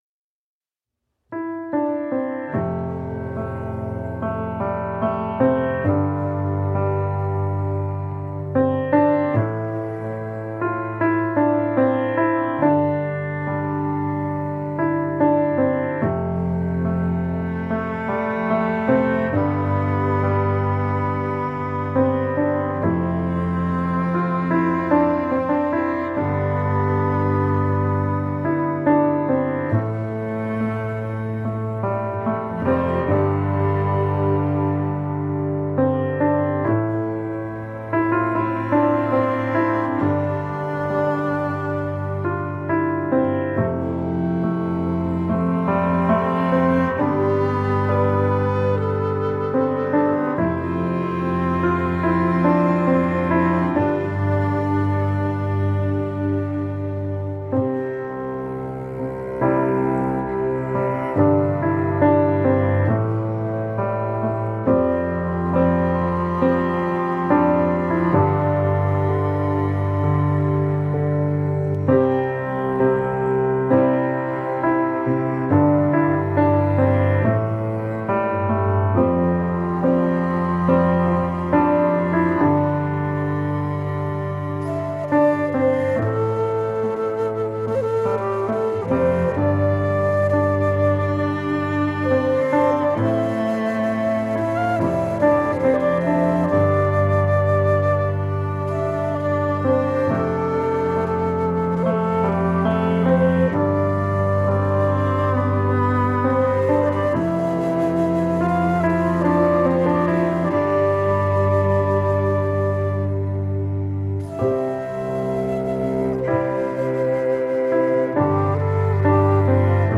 Intriguing piano compositions with dynamic accompaniment.
Tagged as: New Age, Folk, Instrumental